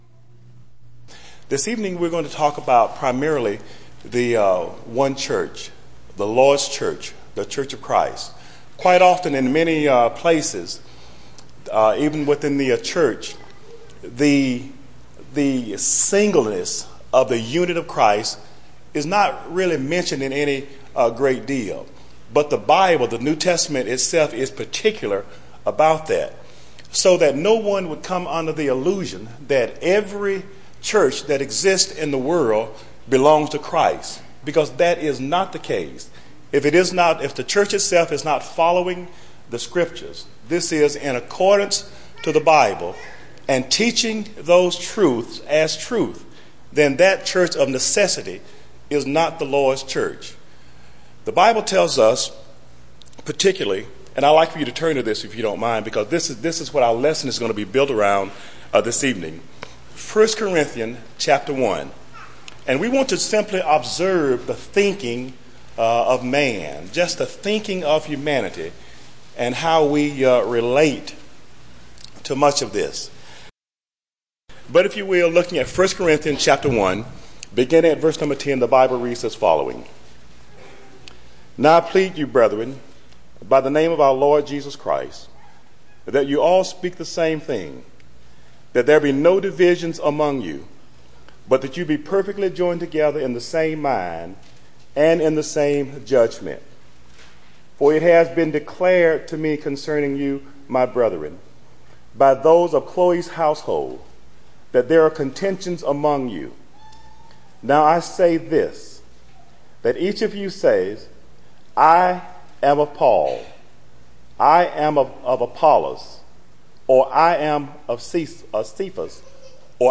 Service: Sun PM Type: Sermon Speaker: Various Young Men